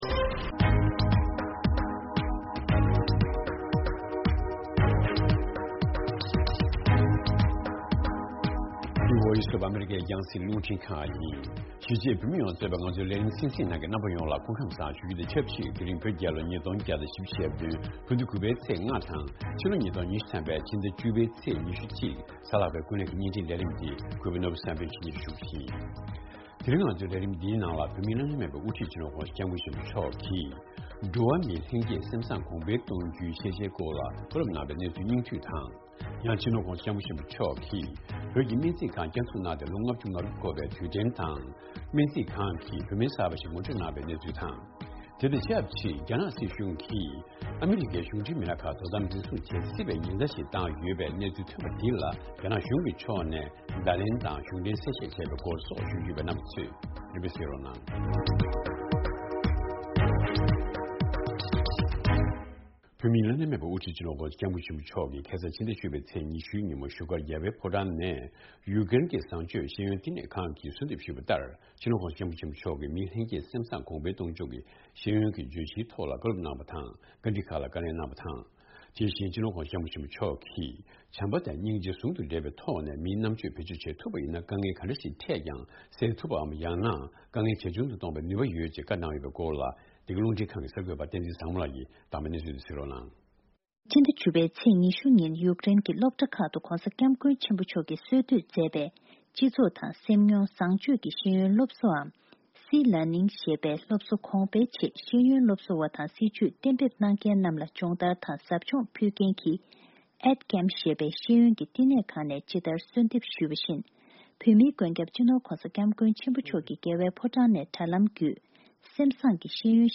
རྒྱ་ནག་གིས་ཡ་ལན་གྱི་ཚུལ་དུ་ཨ་རིའི་མི་སྣ་ཁག་ཅིག་བཀག་ཉར་བྱེད་སྲིད་པ་སོགས་ཀྱི་གསར་འགྱུར་ཡོད། དེ་རིང་གི་ཀུན་གླེང་དུ་ཆབ་སྲིད་བཙོན་ཟུར་སྟག་སྣ་འཇིགས་མེད་བཟང་པོ་ལགས་རྗེས་དྲན་བྱས་ནས་ཁོང་གི་ལོ་རྒྱུས་མི་ཚེའི་མྱོང་ཚོར་ཞེས་པའི་རྩོམ་པ་པོ་དང་། དམ་པ་ཁོང་དང་མཉམ་དུ་རྒྱ་ནག་གི་བཙོན་ཁང་གཅིག་པར་བཙོན་འཇུག་མྱངས་མྱོང་བའི་མི་སྣ་ཞིག་ལ་བཅར་འདྲི་ཞུས་ཡོད།